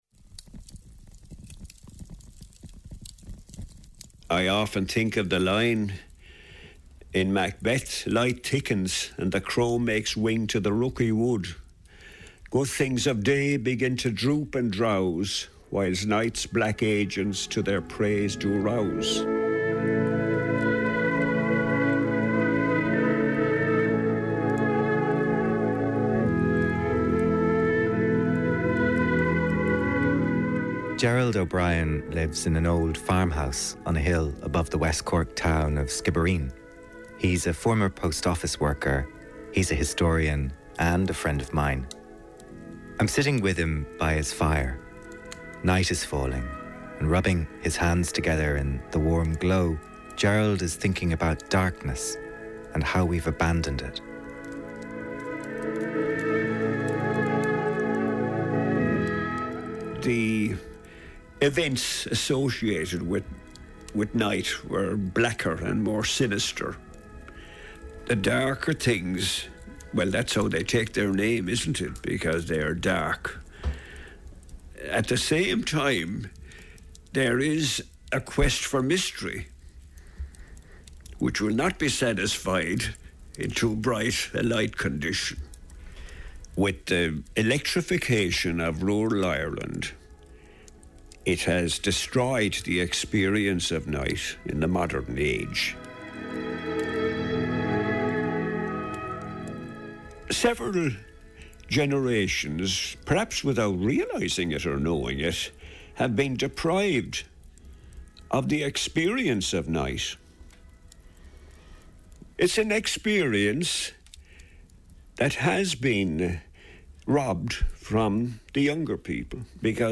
Vocal Chords: In Conversation with Peggy Seeger | The Lyric Feature - 09.08.2020